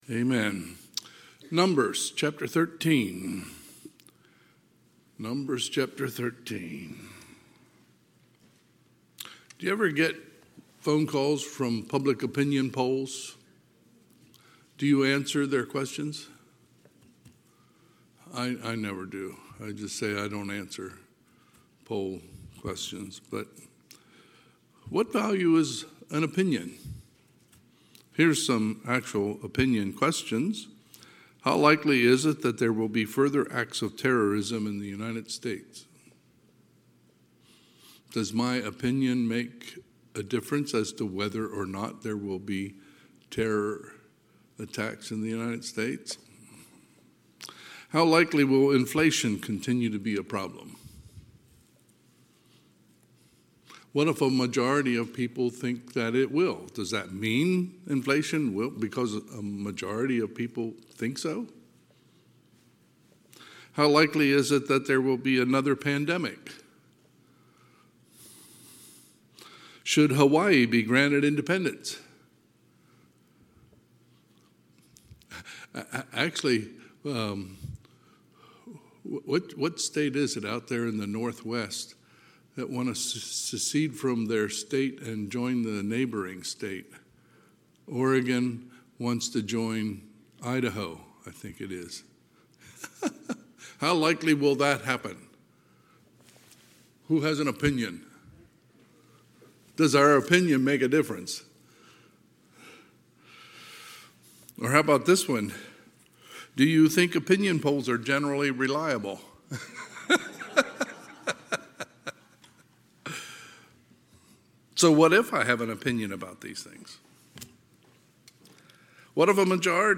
Sunday, February 23, 2025 – Sunday PM